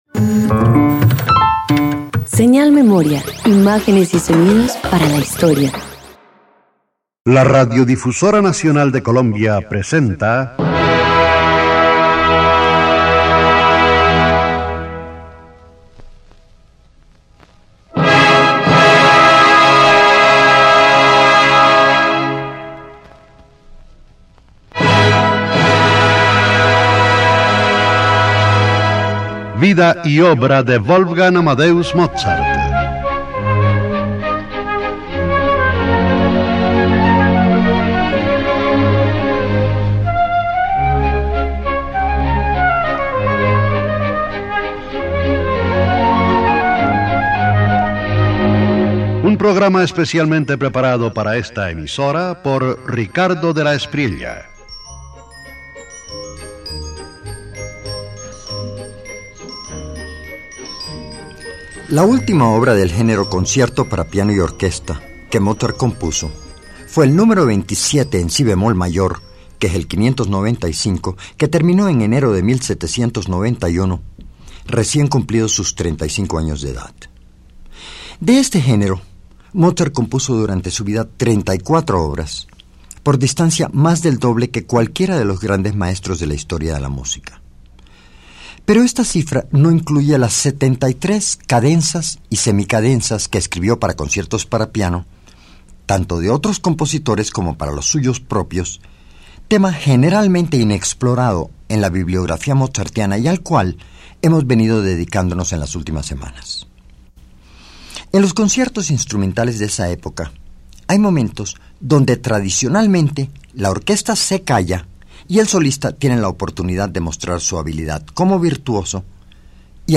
323 Cadenzas para piano y orquesta Parte IV_1.mp3